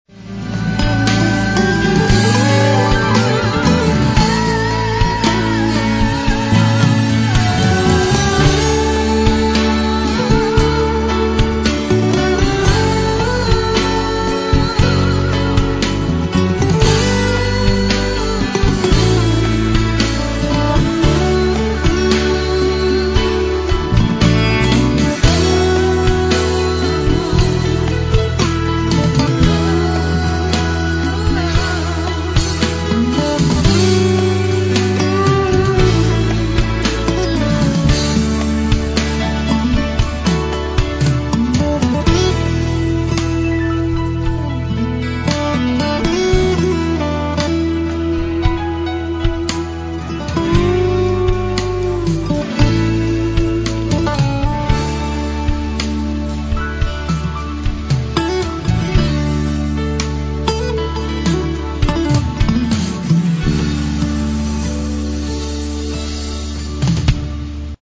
spora jazzy balada, vokal na kraju